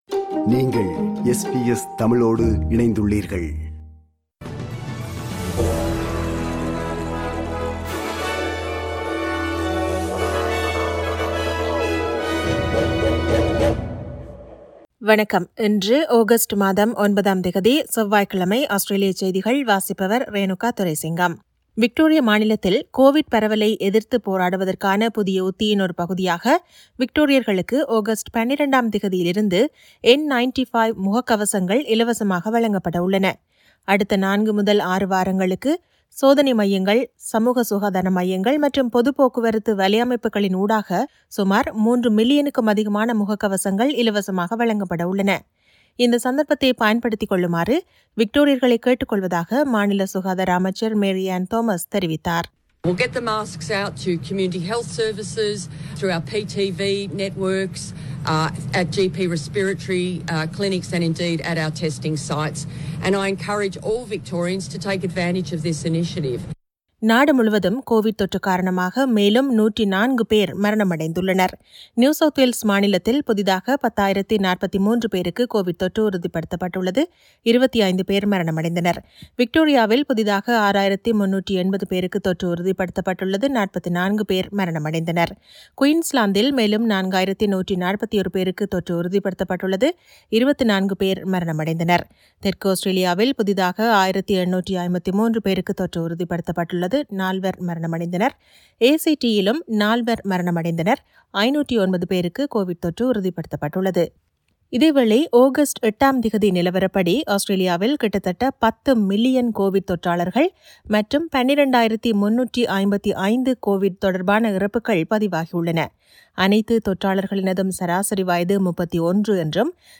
Australian news bulletin for Tuesday 09 Aug 2022.